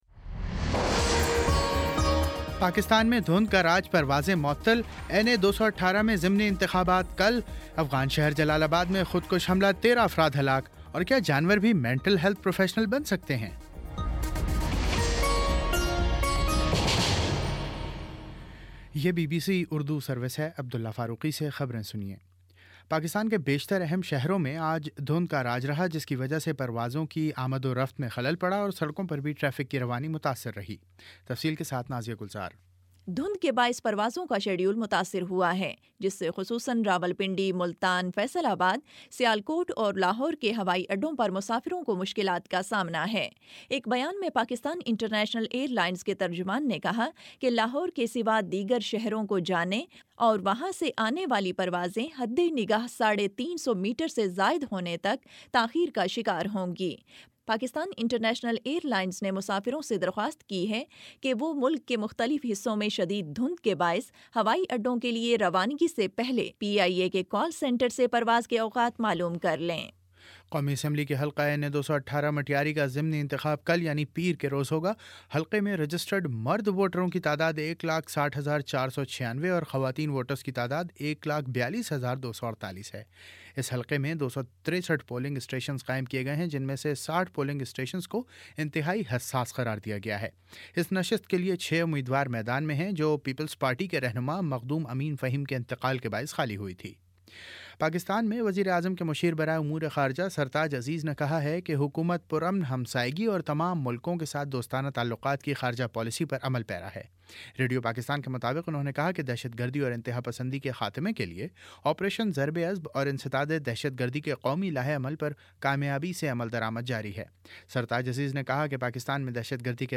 جنوری 17 : شام پانچ بجے کا نیوز بُلیٹن